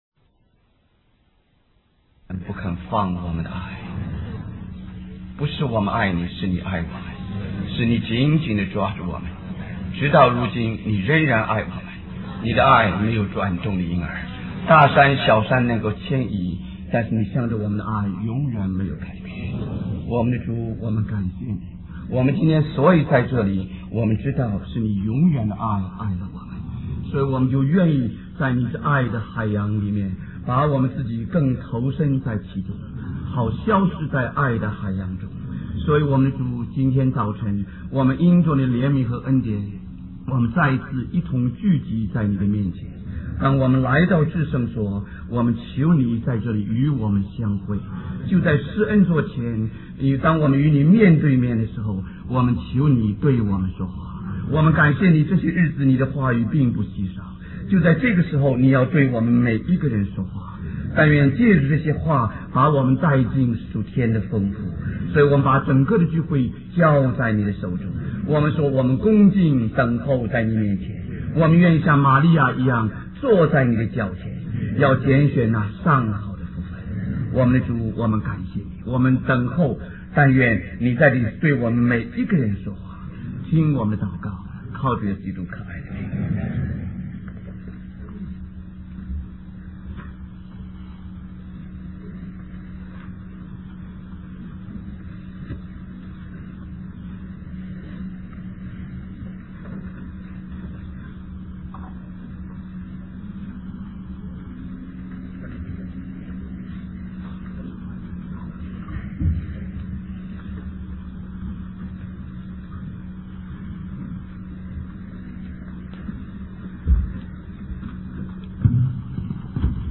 Special Conference For Service, Singapore